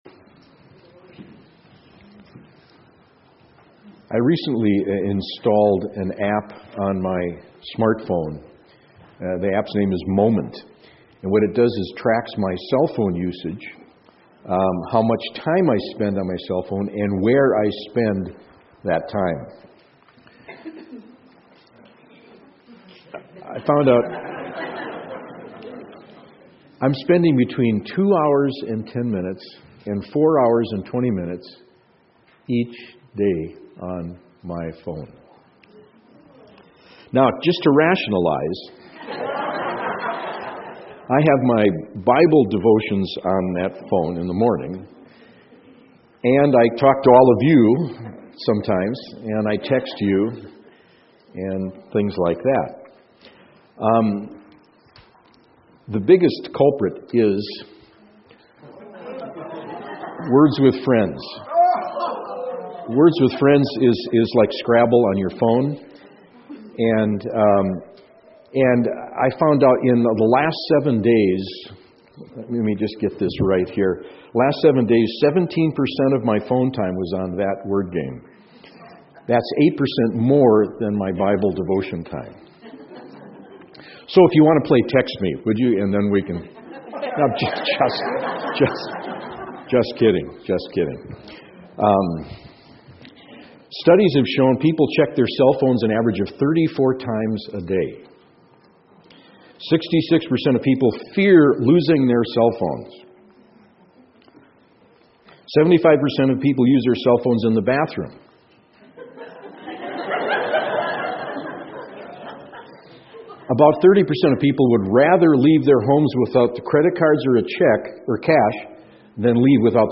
SERMONS Go To War...Over What?